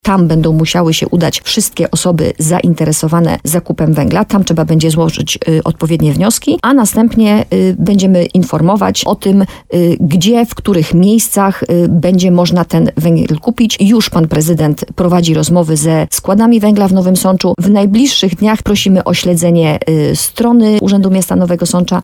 Jak powiedziała dla radia RDN Nowy Sącz, wiceprezydent miasta, Magdalena Majka, urzędnicy chcieli działać w określonych ramach prawnych, aby uniknąć niejasności w sprawie dystrybucji węgla.
– W pierwszej kolejności działania podejmie Miejski Ośrodek Pomocy Społecznej – mówi zastępca prezydenta Nowego Sącza, Magdalena Majka.